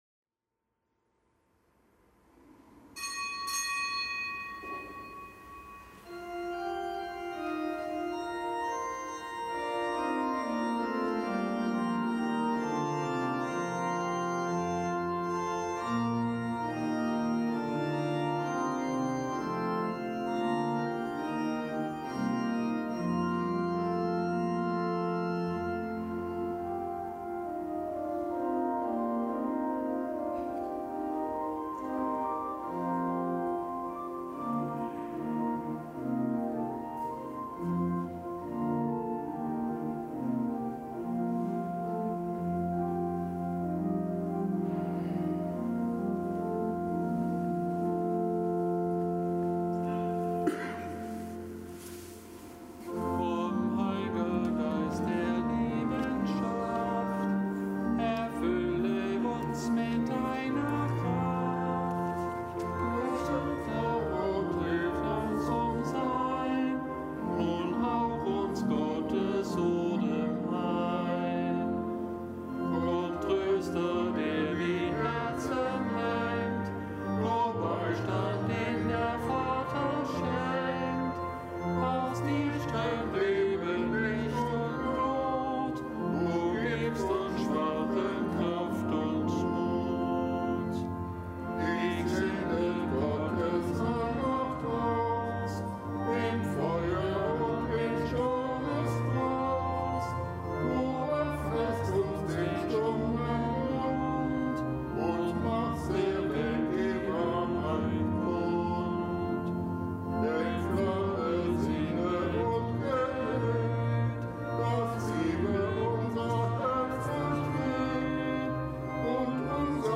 Kapitelsmesse am Mittwoch der vierten Woche im Jahreskreis
Kapitelsmesse aus dem Kölner Dom am Mittwoch der vierten Woche im Jahreskreis. Nichtgebotener Gedenktag des Heiligen Rabanus Maurus, einem Bischof von Mainz (RK).